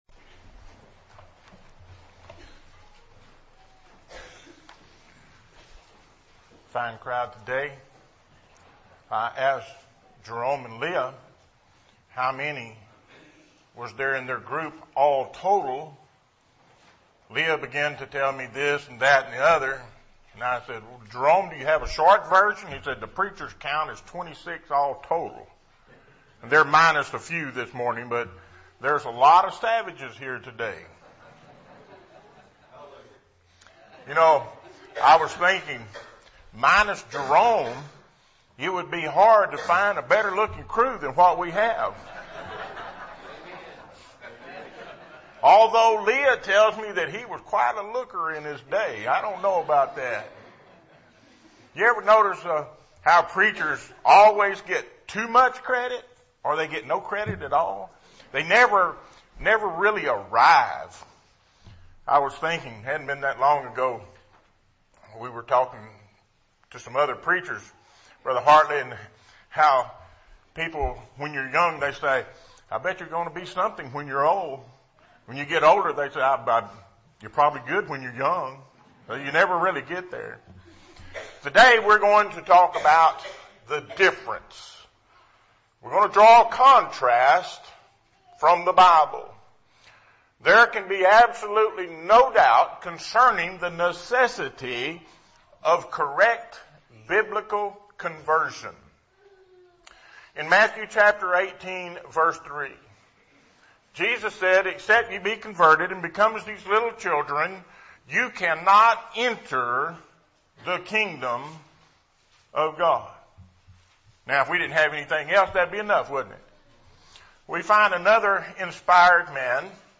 Series: Sermon